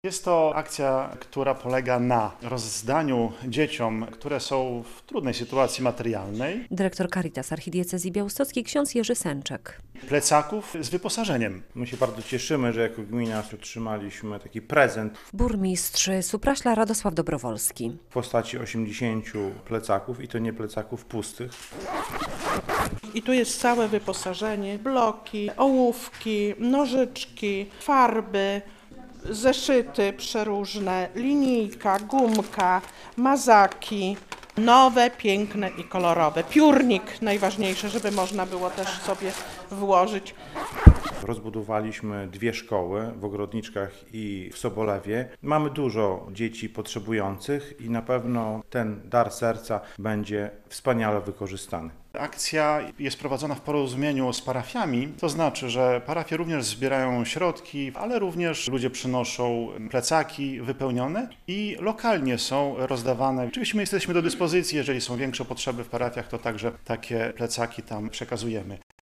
Plecaki dla potrzebujących uczniów - relacja
Rozbudowaliśmy dwie szkoły, w Ogrodniczkach i w Sobolewie, mamy dużo dzieci potrzebujących i na pewno ten dar serca będzie wspaniale wykorzystany - mówi burmistrz Supraśla Radosław Dobrowolski.